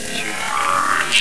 EVP (Electronic Voice Phenomena)
Some examples of EVP recordings (in wav format)